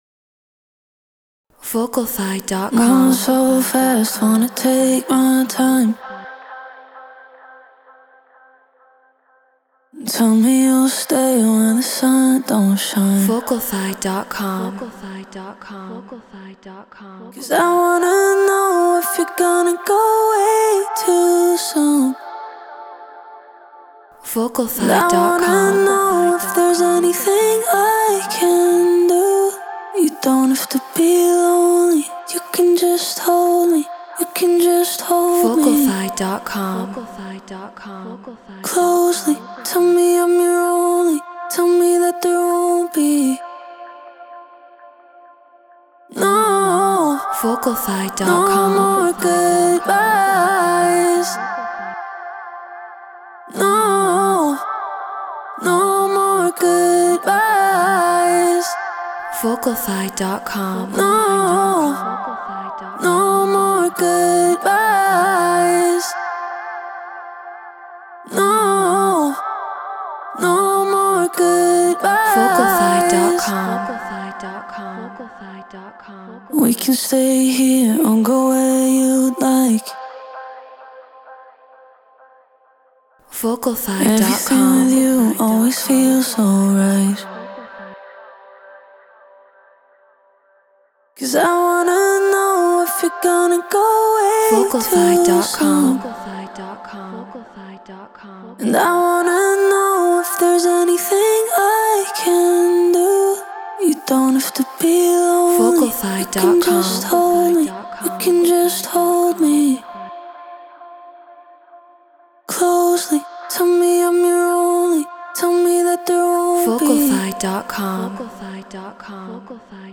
House 130 BPM Fmaj
Shure SM7B Apollo Solo Logic Pro Treated Room